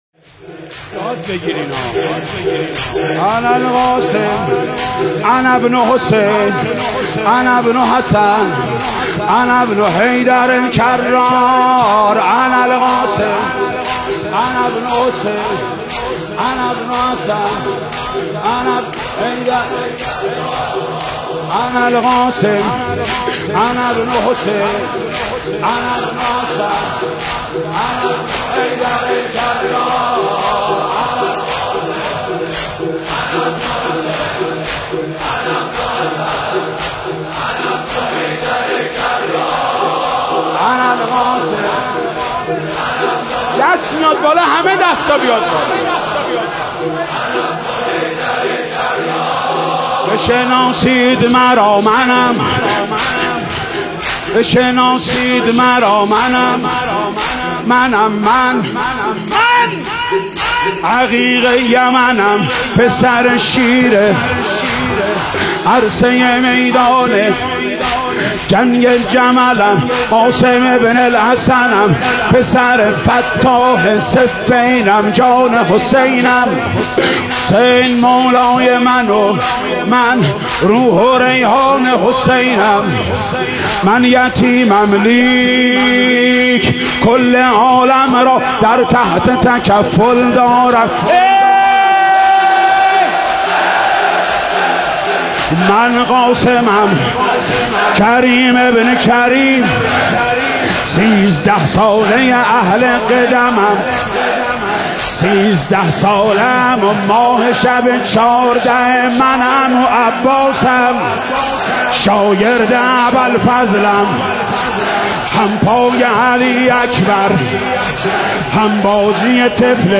دسته بندی : نوحه و مداحی تاریخ : پنجشنبه ۵ مرداد ۱۴۰۲